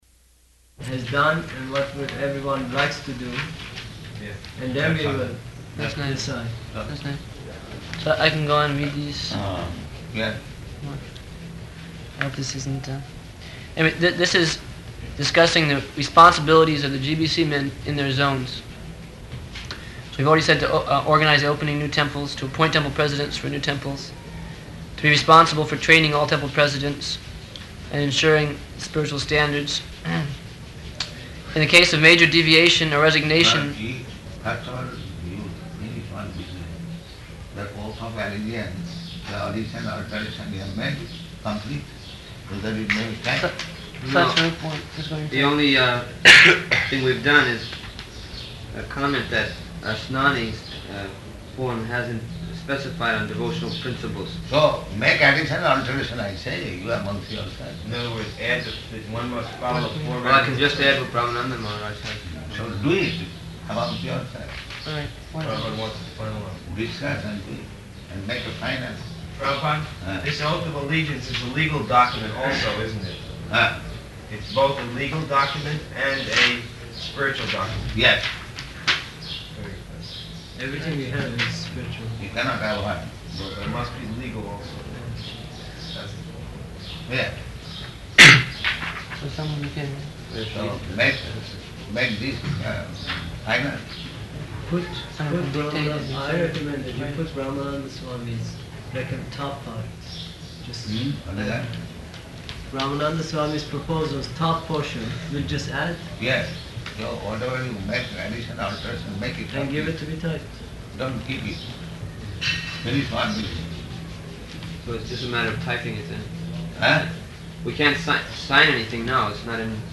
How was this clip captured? -- Type: Conversation Dated: March 27th 1975 Location: Māyāpur Audio file